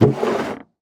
Minecraft Version Minecraft Version 25w18a Latest Release | Latest Snapshot 25w18a / assets / minecraft / sounds / block / barrel / open2.ogg Compare With Compare With Latest Release | Latest Snapshot